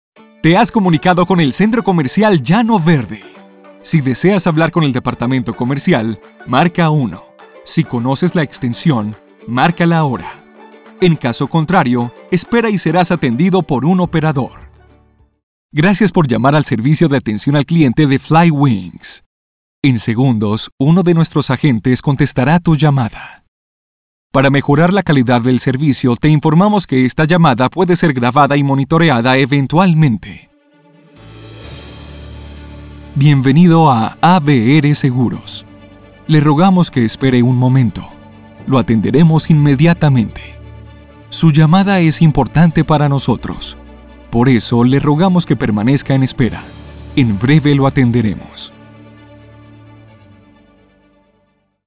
Very wide voice range.
IVR Phone System Voice Over Demo
Español Neutro Latino - Spanish Latin America - English Latino